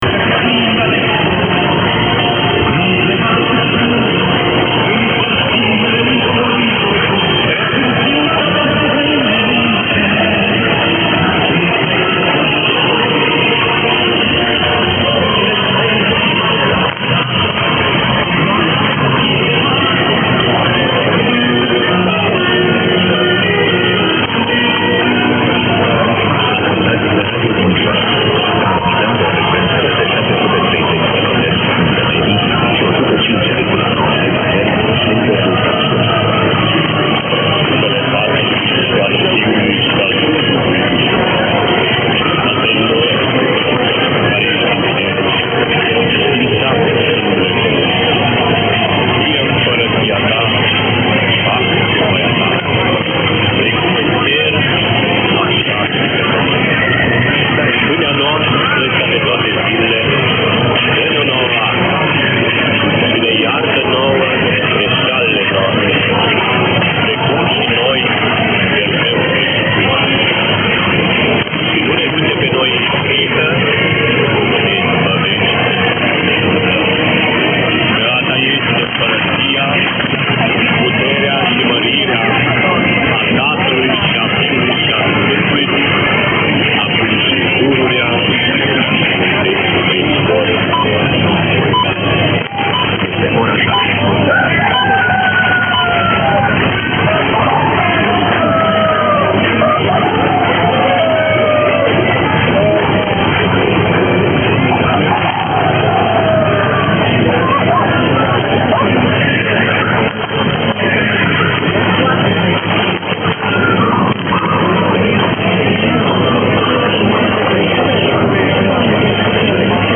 WHO HAS THE COCKERAL CROWING AT 0300???
Does anyone know which station has a cockeral crowing at 0300??
The clip below has some of the lingo either side of the toth.